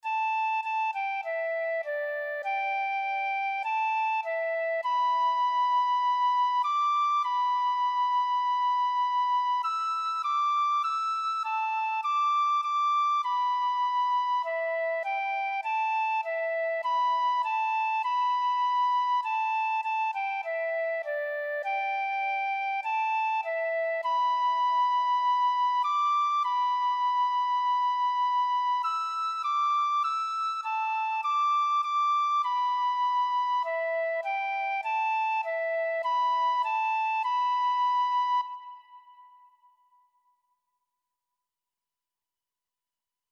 “Canto del teatro sobre Moisés„